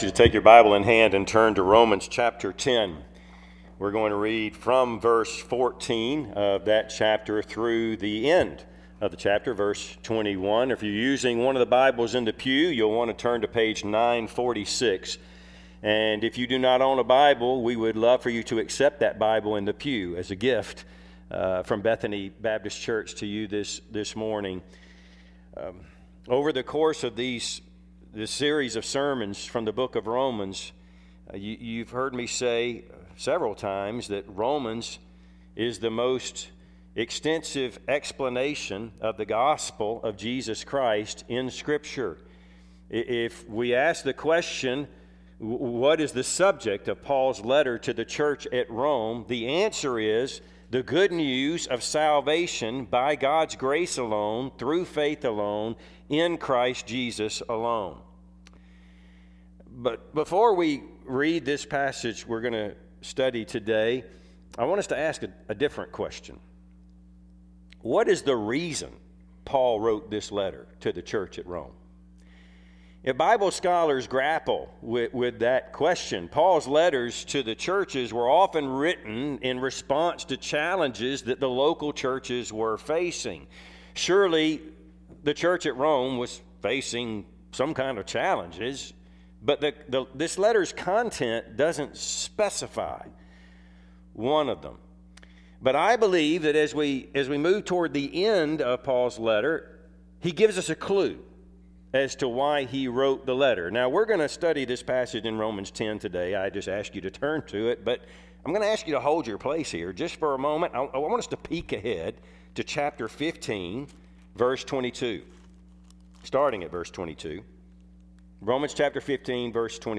Service Type: Sunday AM Topics: Evangelism , Faith , Missions , Salvation